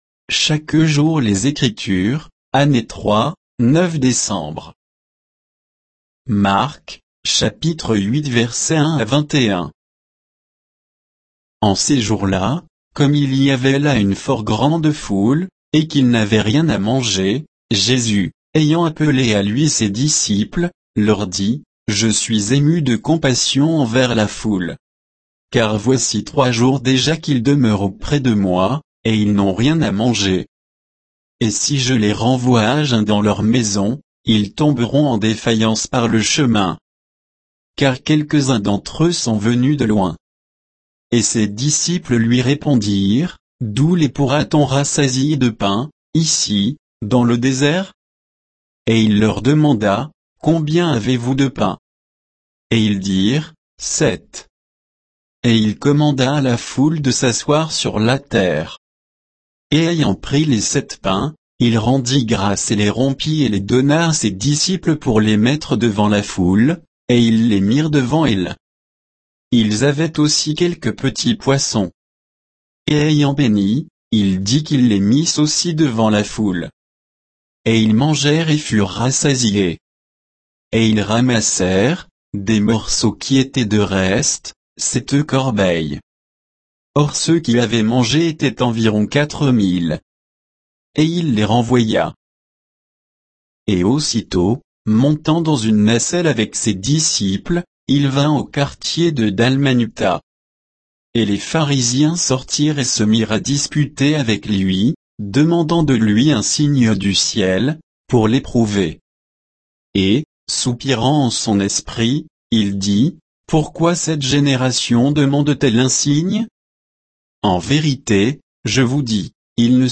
Méditation quoditienne de Chaque jour les Écritures sur Marc 8, 1 à 21